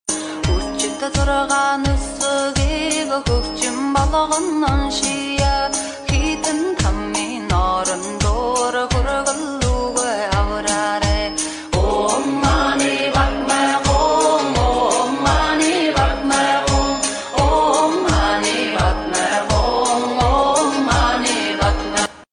Healing music Hymn of sound effects free download